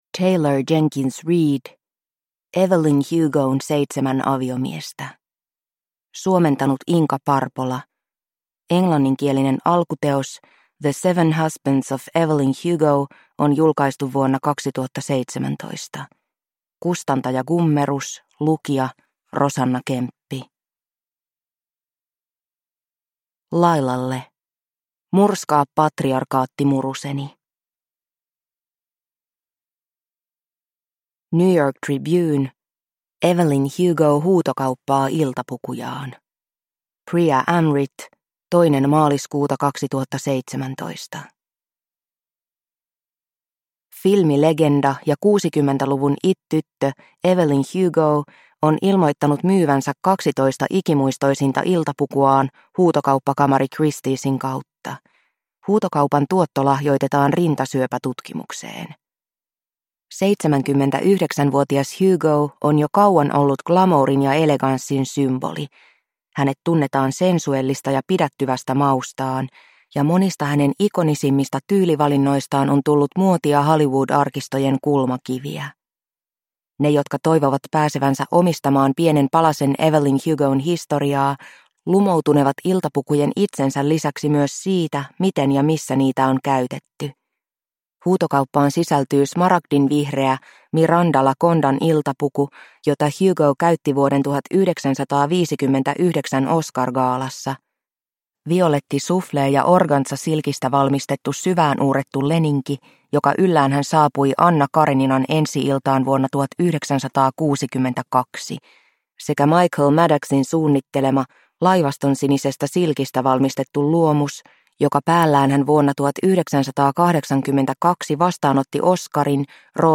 Evelyn Hugon seitsemän aviomiestä – Ljudbok – Laddas ner